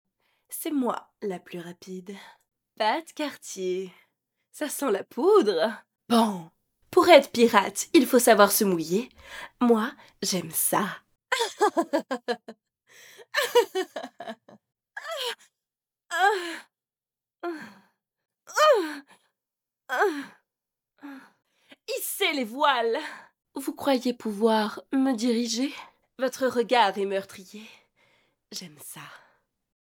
Démo Jeux Vidéos Femme
4 - 45 ans - Mezzo-soprano